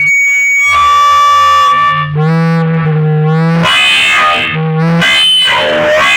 RADIOFX  2-L.wav